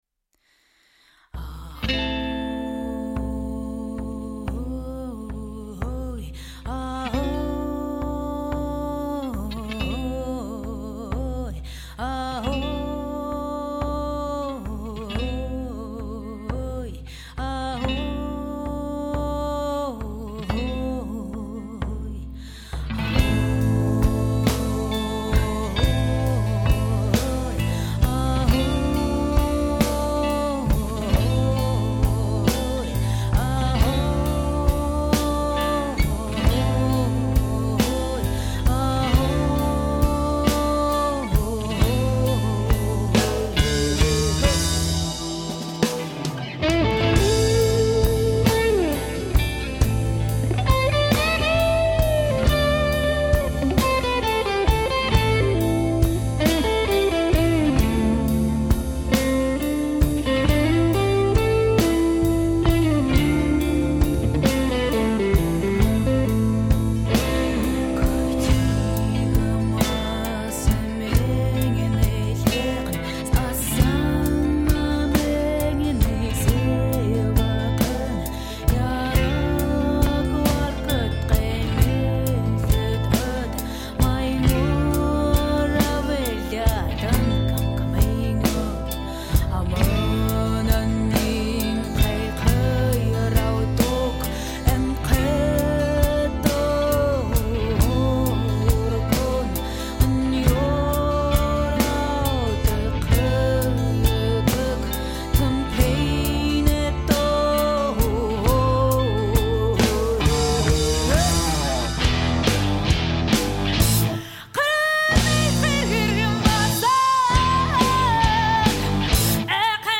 Этно-этно-блюз.